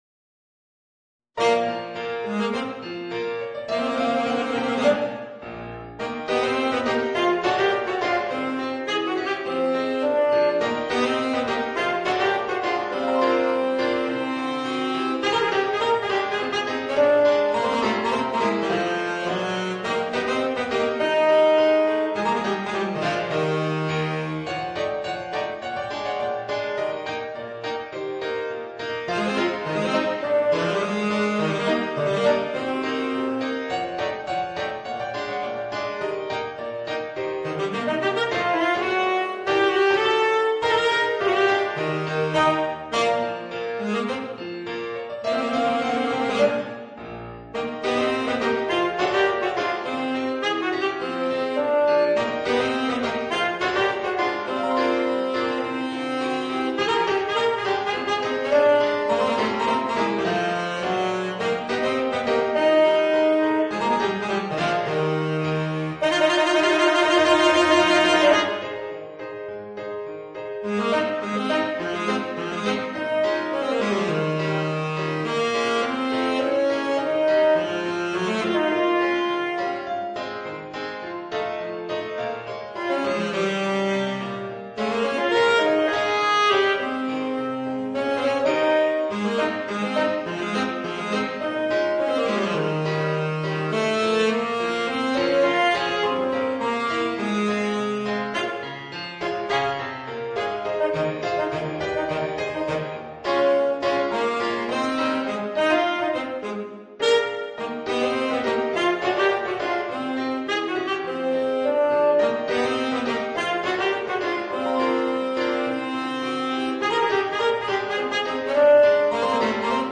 Saxophone ténor & piano